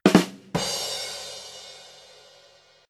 Platillo chiste